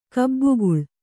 ♪ kabbu